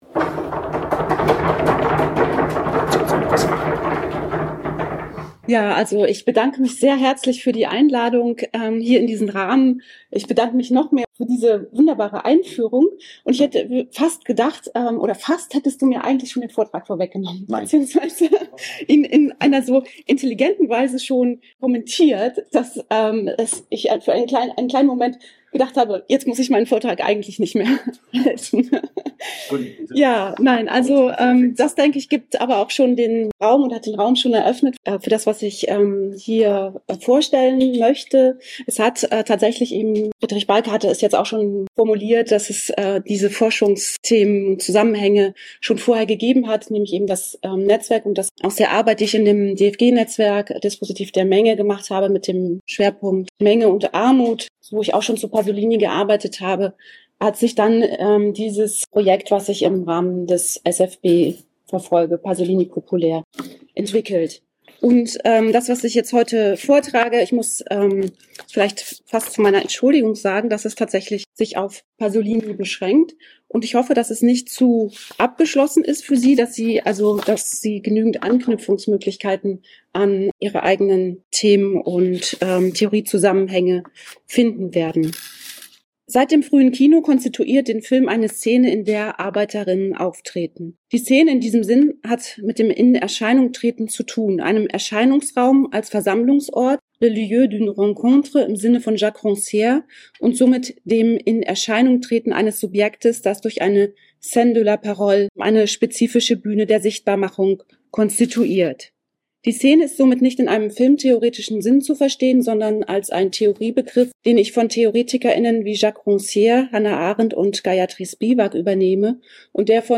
Ausgehend von Hannah Arendts Bestimmung des öffentlichen Raums als Erscheinungsraum und Gayatri Spivaks Wer spricht? lotet der Vortrag am Beispiel von Pier Paolo Pasolinis Dokumentarfilm Comizi d’amore (1964) die Möglichkeiten aus, das Kino in Hinblick auf Fragen nach dem Erscheinen zu perspektivieren: Wer tritt in Erscheinung?